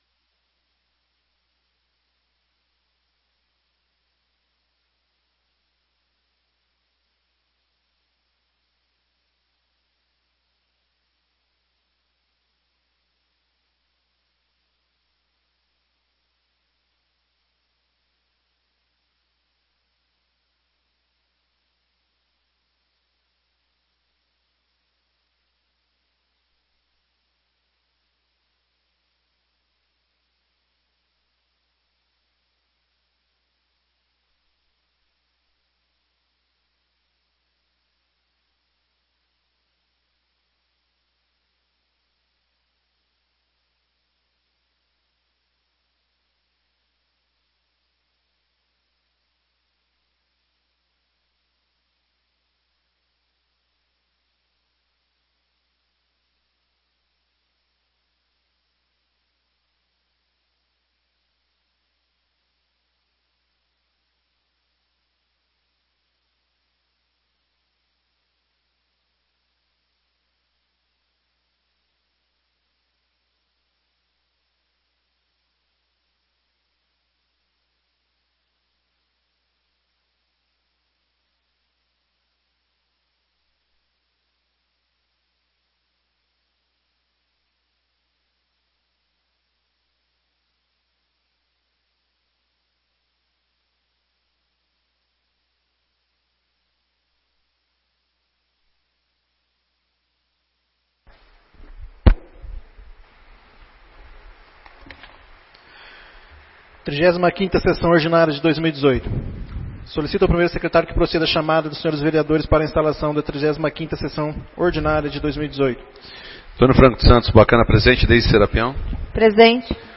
35ª Sessão Ordinária de 2018